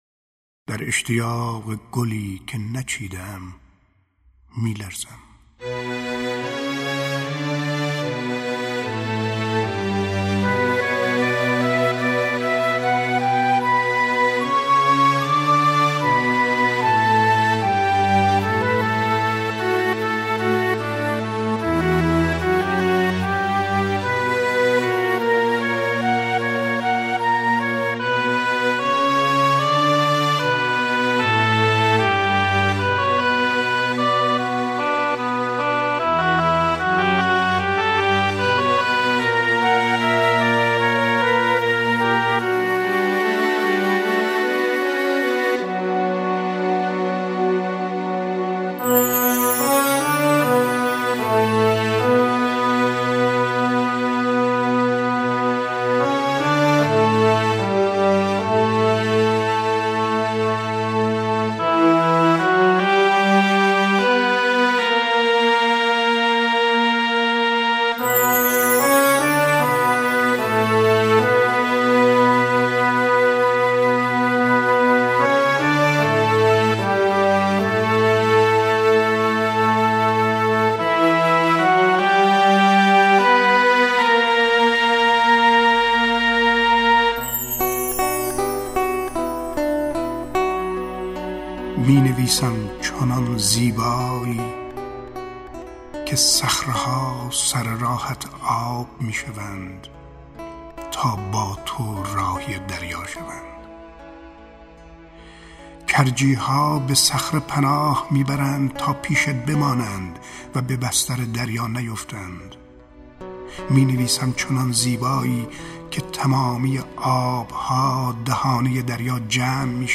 دانلود دکلمه در اشتیاق گلی که نچیده ام با صدای شمس لنگرودی
گوینده :   [شمس لنگرودی]